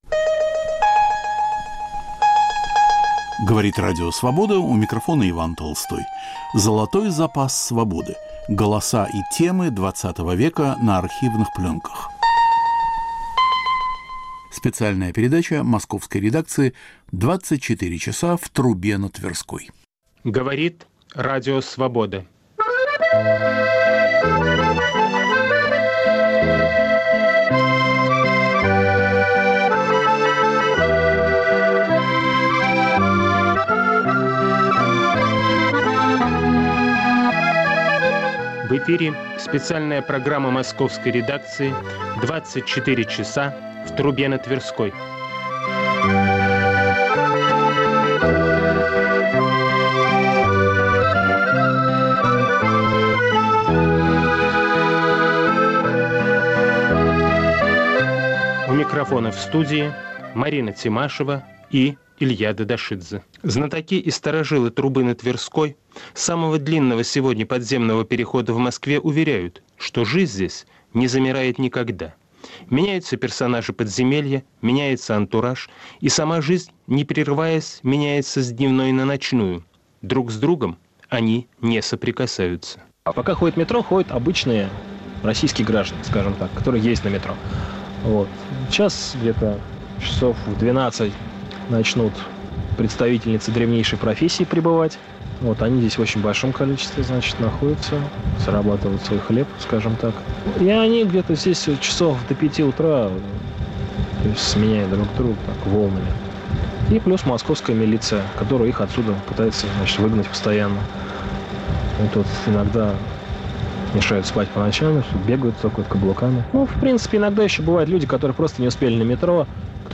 Специальная передача Московской редакции. Жизнь в самом длинном подземном переходе в Москве. Рассказывают его знатоки и старожилы.